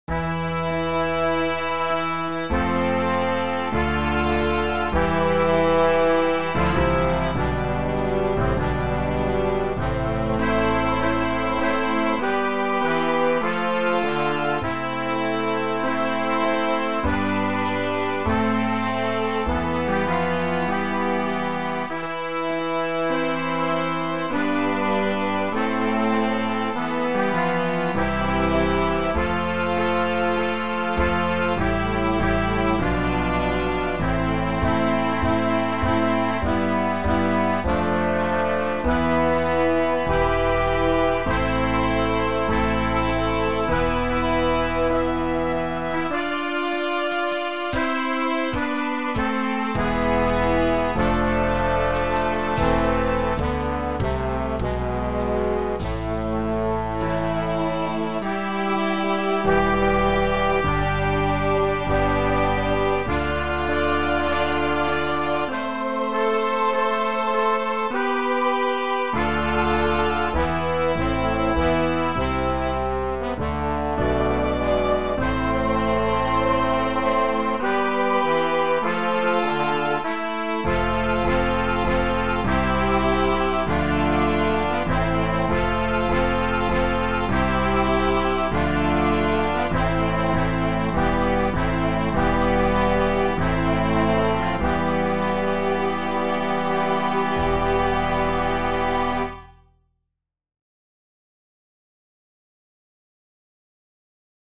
Style: Swing/Classical
Instrumentation: Standard Jazz Band